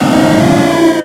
Cri de Noarfang dans Pokémon X et Y.